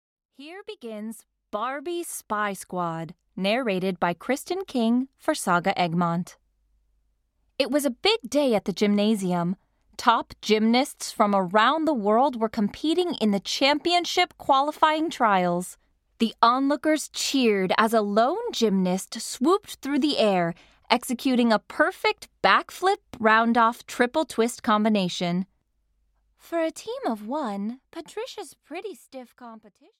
Barbie - Spy Squad (EN) audiokniha
Ukázka z knihy